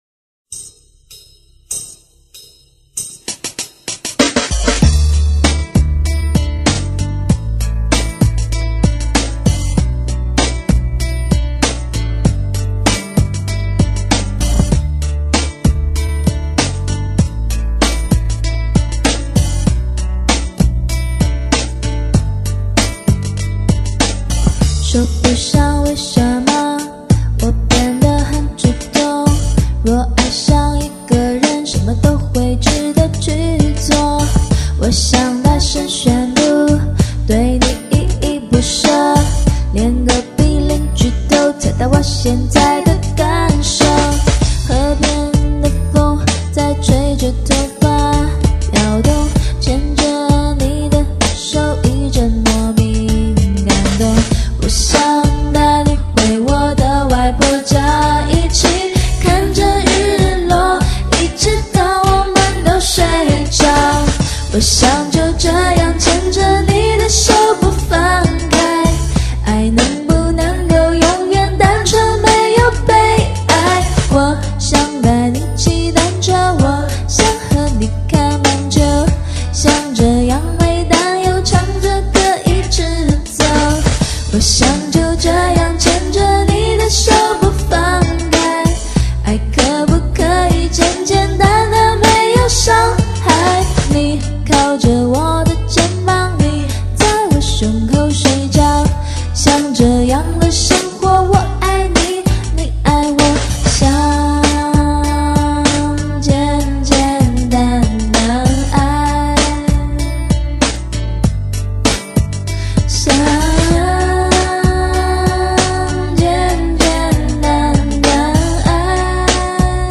翻唱